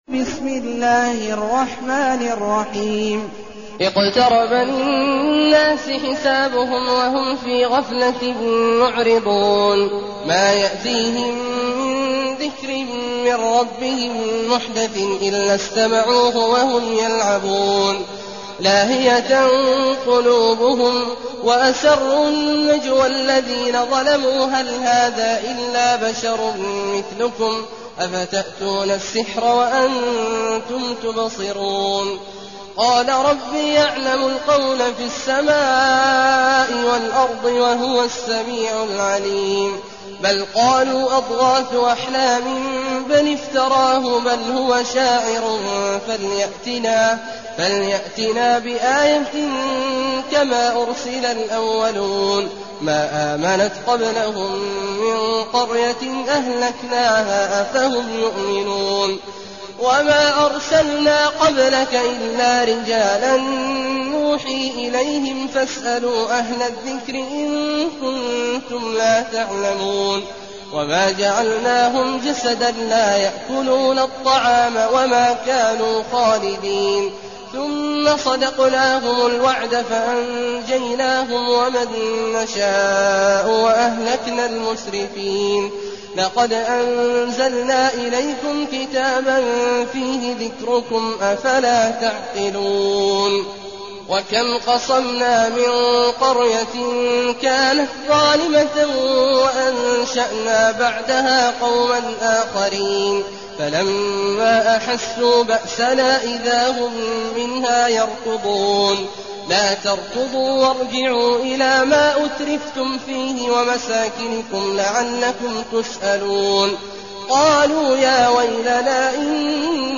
المكان: المسجد الحرام الشيخ: عبد الله عواد الجهني عبد الله عواد الجهني الأنبياء